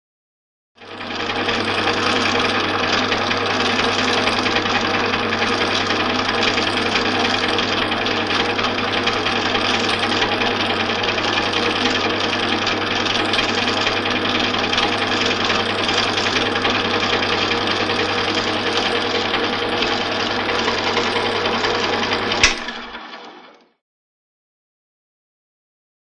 Airport; Baggage; Baggage Conveyor Belt On, Running And Then Off.